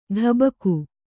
♪ ḍhabaku